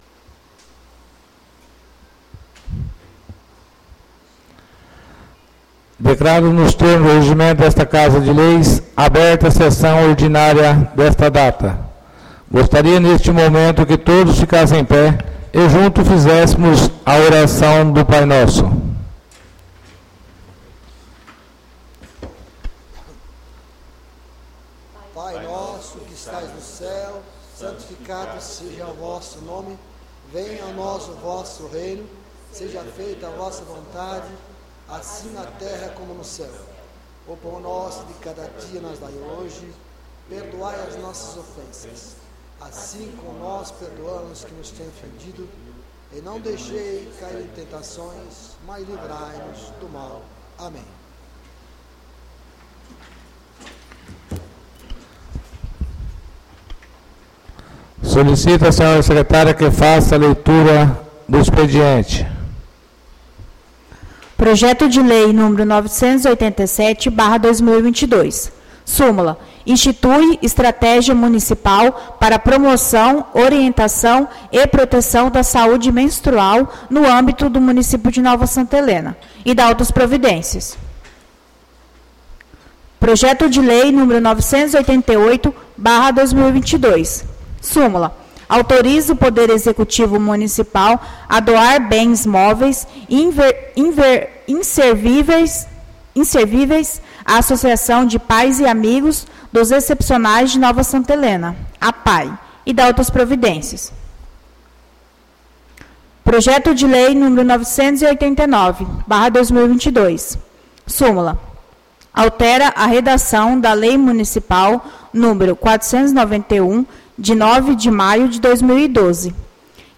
ÁUDIO SESSÃO 01-08-22